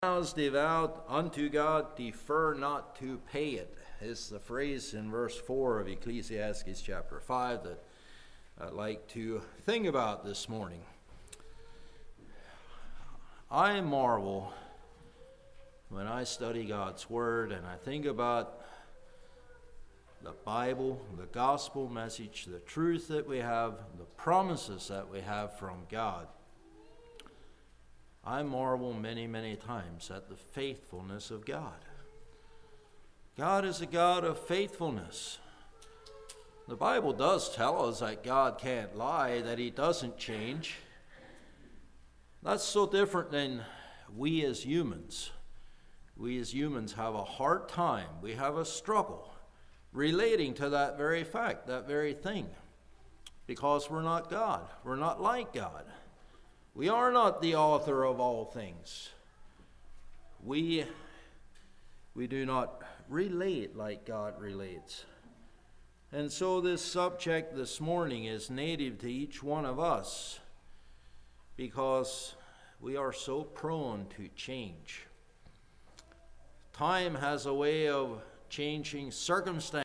A practical sermon on how God views the promises we make to God and others!